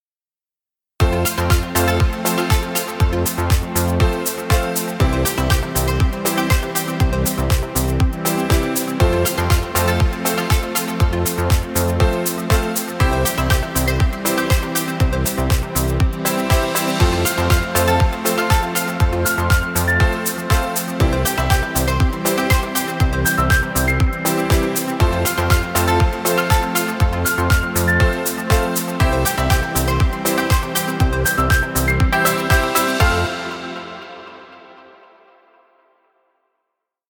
Dance music for video.